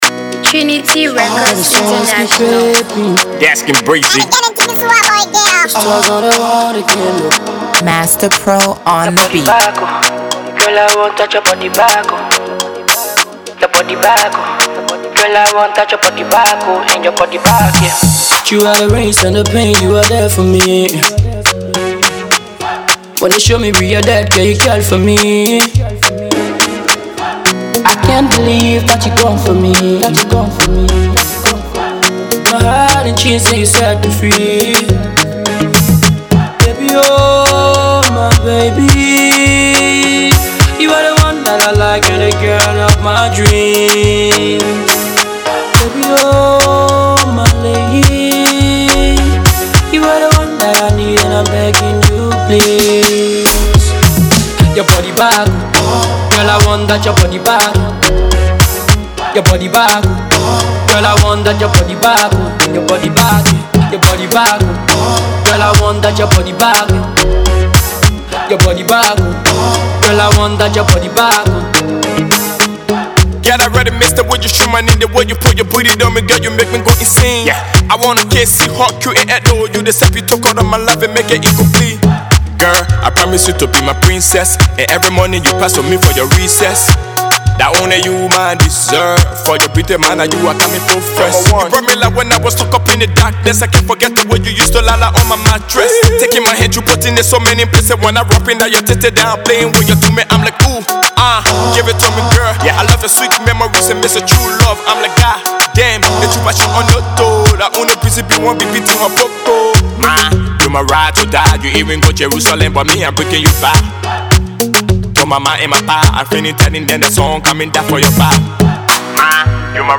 Categories: Afro-PopAfrobeatsUnderground Artists